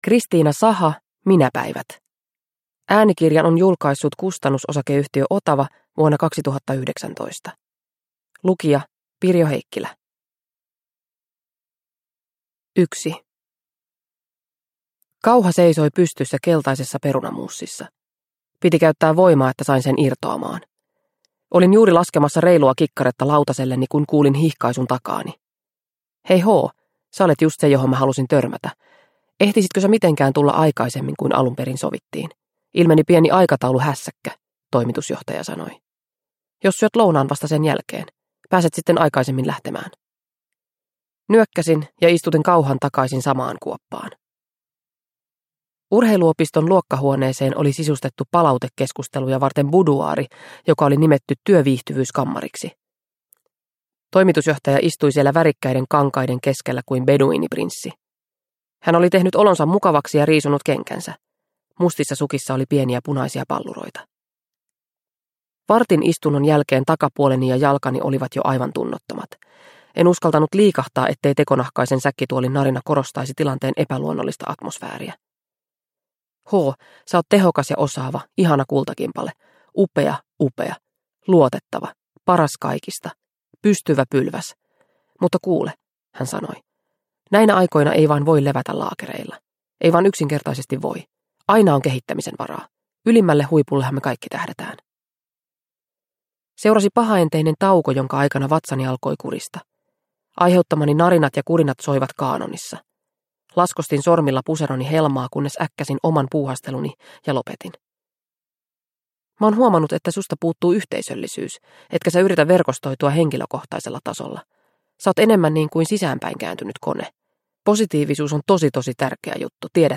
Minäpäivät – Ljudbok – Laddas ner